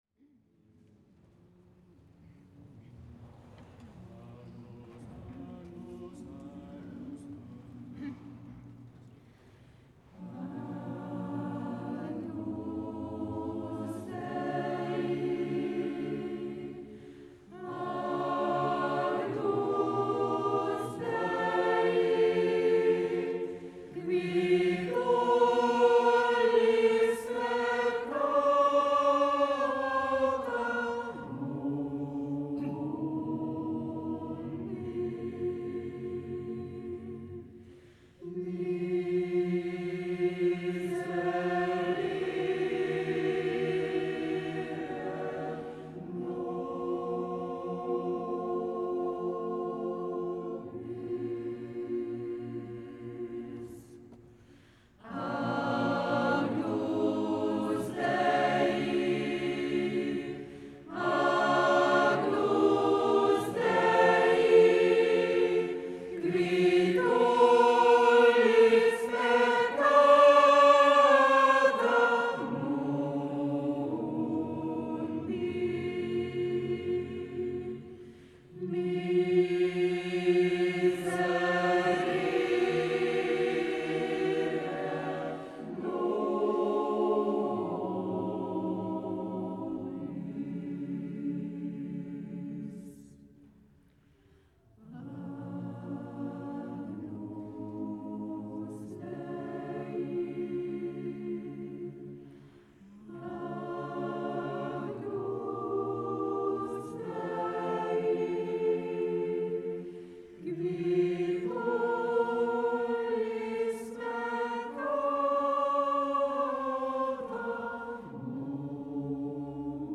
A Fővárosi Énekkar előadásaiból
Karmelita templom, Angyalföld
A fenti fölvételek Sony MZ-R900 minidiszk eszközzel, ECM-MS 907 mikrofonnal készültek, ATRAC tömörítéssel (jobb akkoriban nem állt rendelkezésre).